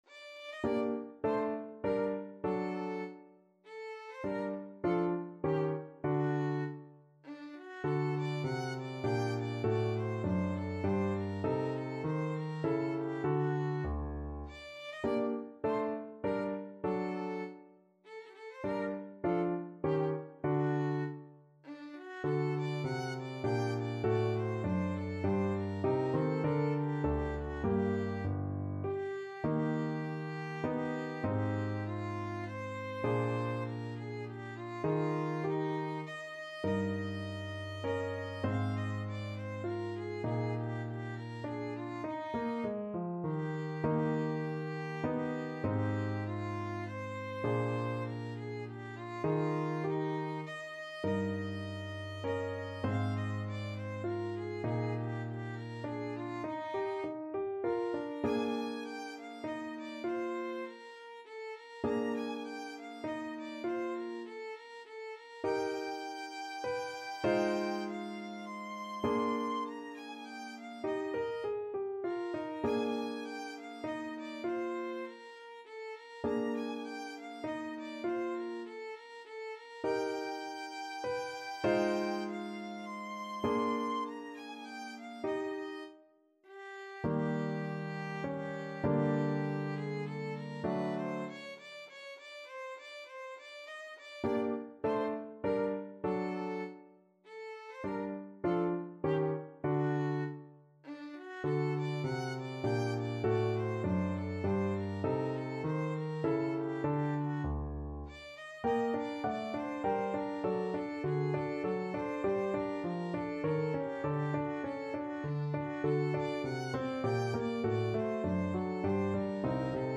Classical Beethoven, Ludwig van 11 Bagatelles Op.119 No.1 Violin version
Violin
G minor (Sounding Pitch) (View more G minor Music for Violin )
Allegretto
3/4 (View more 3/4 Music)
Classical (View more Classical Violin Music)